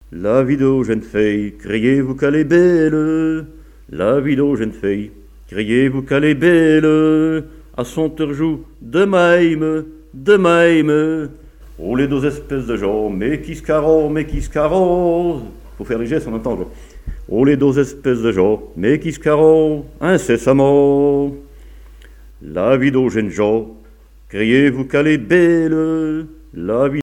Enumératives
Genre strophique
Chansons et témoignages sur le chanson et la musique